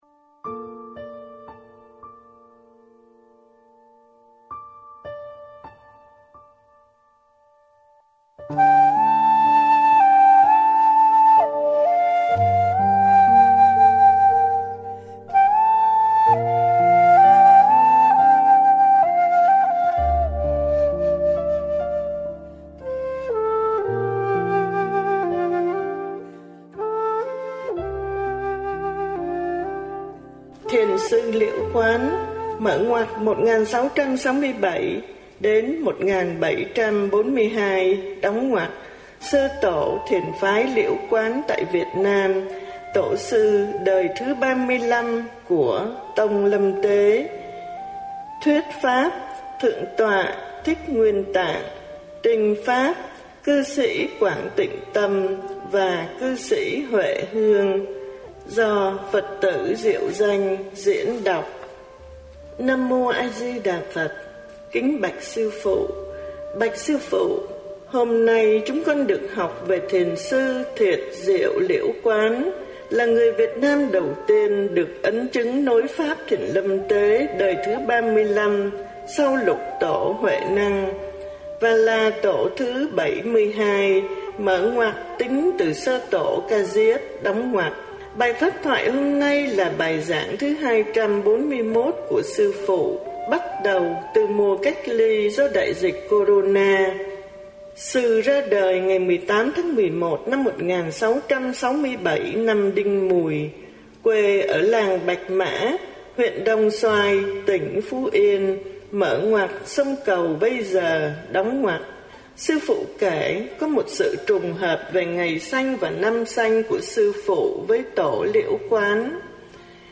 Thiền sư Viên Học, Đời thứ 17, Thiền Phái Tỳ Ni Đa Lưu Chi - Sách Nói - Trang Nhà Quảng Đức